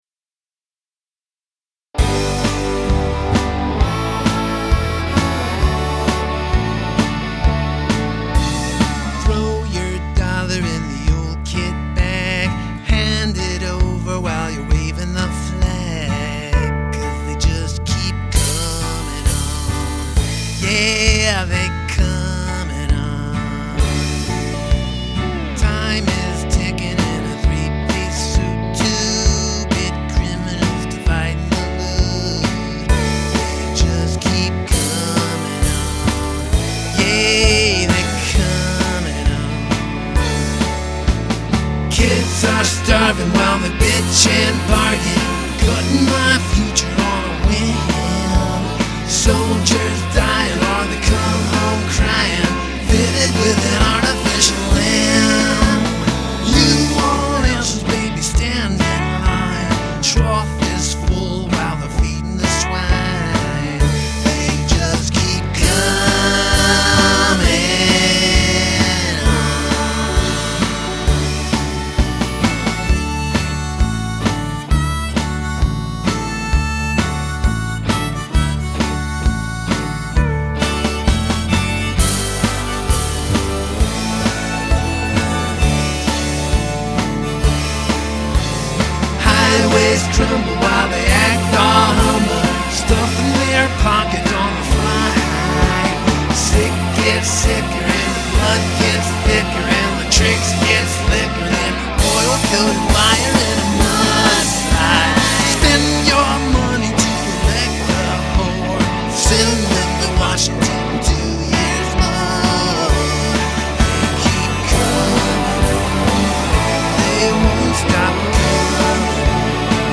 guitars
mandolin